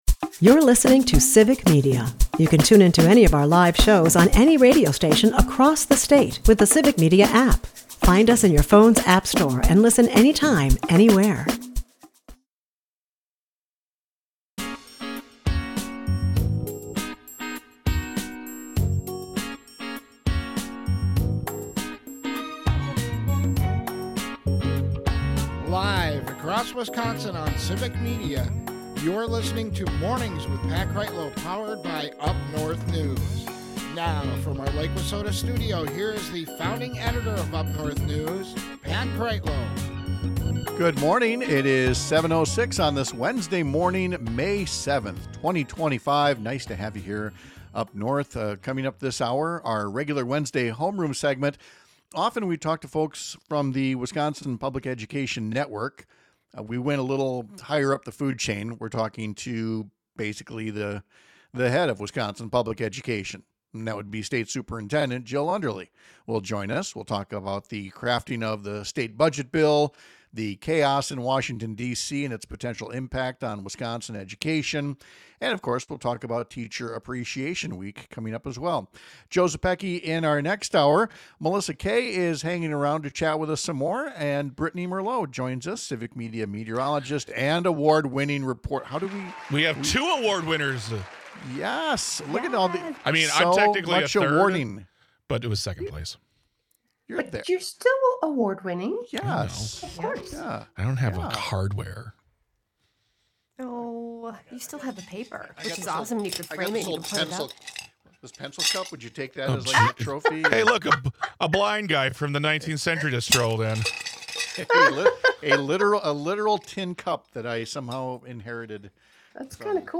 We have a special guest for our weekly Homeroom segment: Dr. Jill Underly, state superintendent of public instruction.
We’ll talk about Teacher Appreciation Week, the status of the state budget debate in Madison, and the harm being caused by President Trump’s efforts to gut essential educational support. We’ll also visit with Congressman Mark Pocan about the difficulty getting Trump officials to acknowledge tariffs are taxes on consumers.